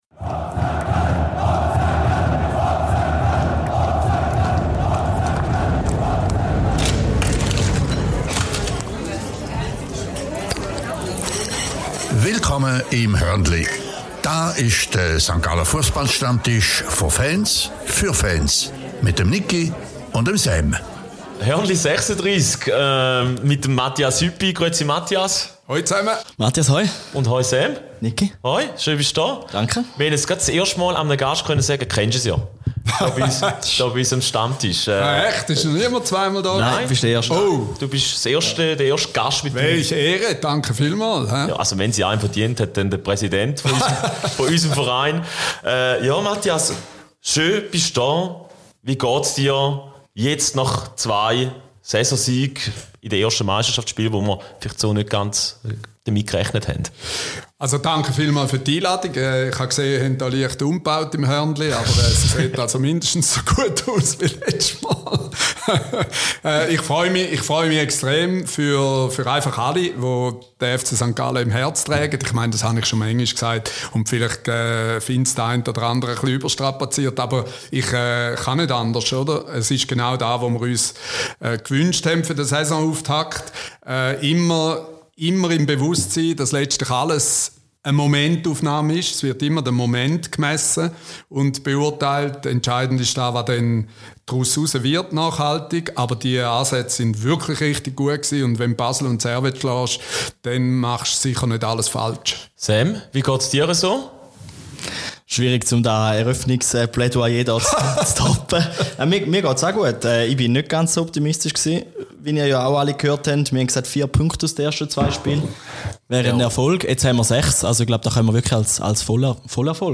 Am Stammtisch werden deshalb auch die „strengeren“ Themen wie die Umstrukturierung, die Abgänge zweier Vereinsmitarbeitern, die Kommunikationsarbeit und das Gemeinschaftsgefühl kritisch besprochen. Ungefiltert, ehrlich, spontan.